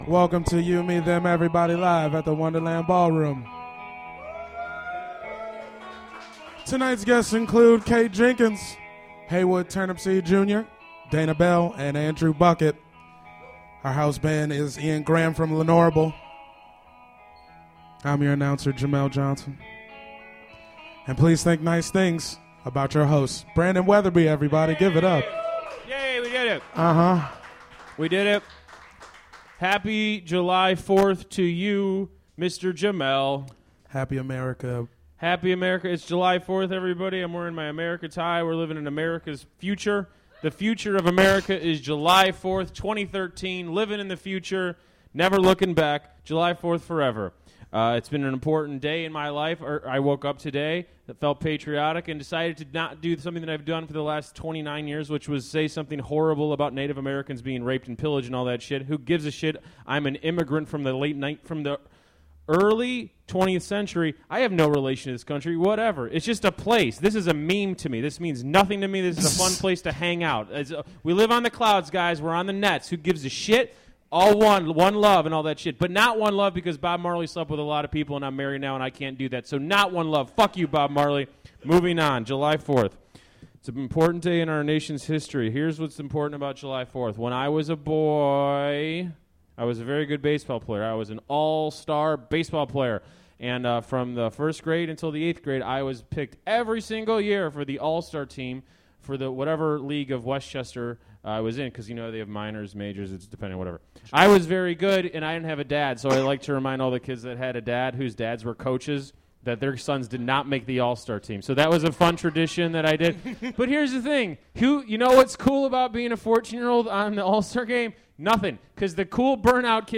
Live at the Wonderland Ballroom
Wonderland Ballroom for venue.